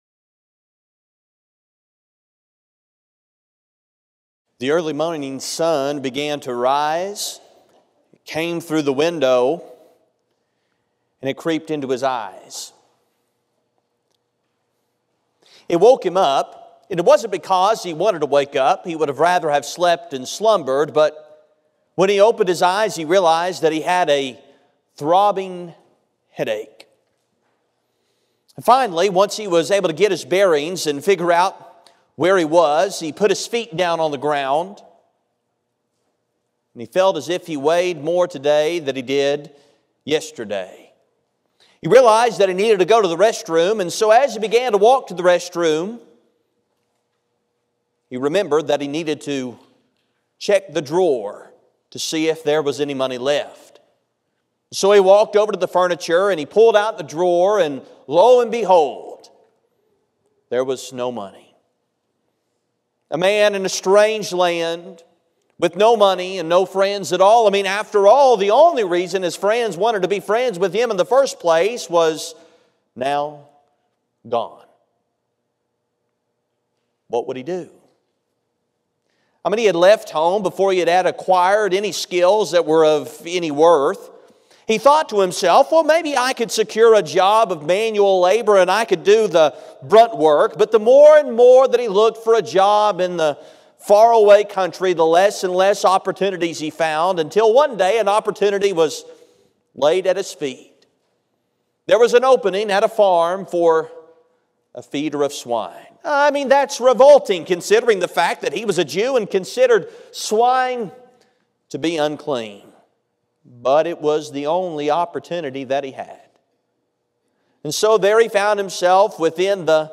The sermon is from our live stream on 10/27/2024